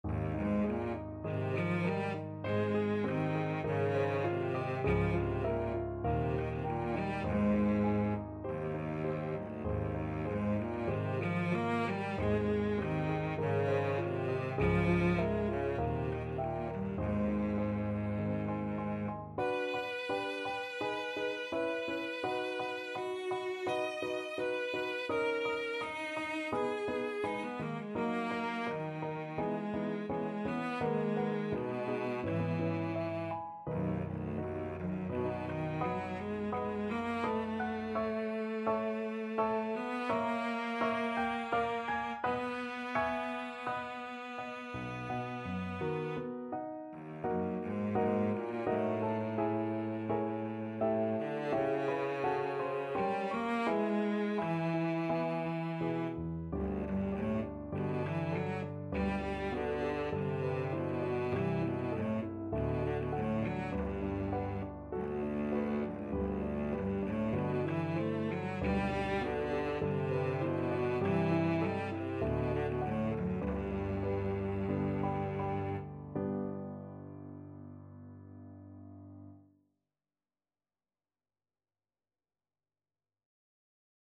Db3-Eb6
4/4 (View more 4/4 Music)
Moderato
Classical (View more Classical Cello Music)